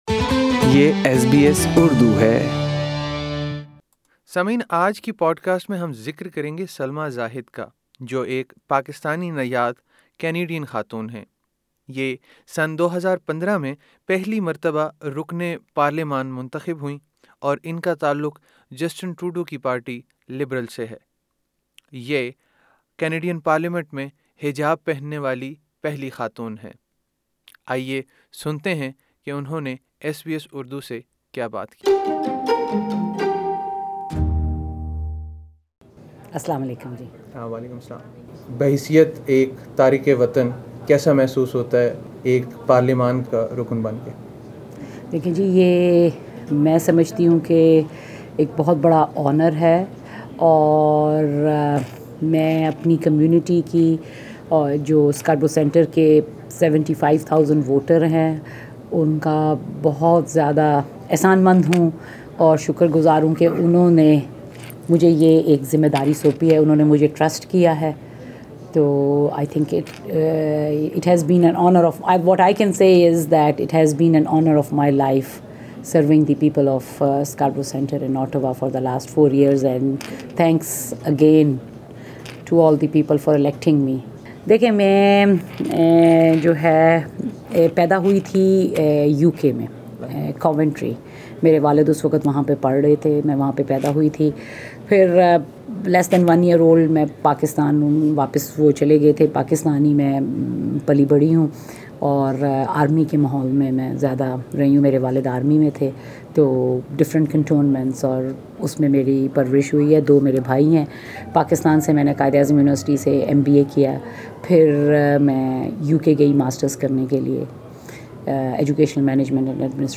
سلمیٰ زاہد انگلستان میں پیدا ہوئیں- جامعہ قائداعظم اسلام آباد اور جامعہ لندن سے تعلیم حاصل کی- سن ۱۹۹۹ میں کینیڈا منتقل ہوئیں- آپ سن ۲۰۱۵ میں پہلی مرتبہ رکنِ پارلیمان منتخب ہوئیں- ایس بی ایس اردو سے گفتگو میں مزید انہوں نے کیا کہا سنیے اس پوڈکاسٹ میں